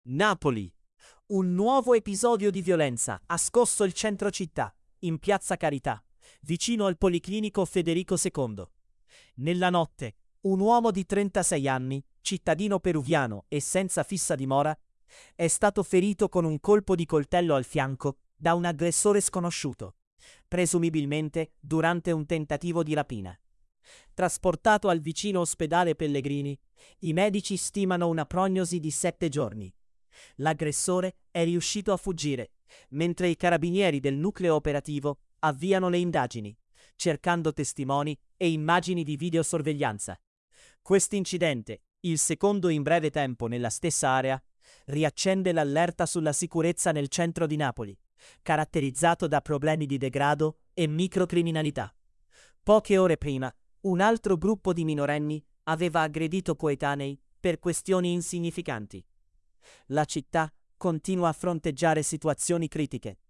napoli-piazza-carita-accoltellato-un-senzatetto-peruviano-tts-1.mp3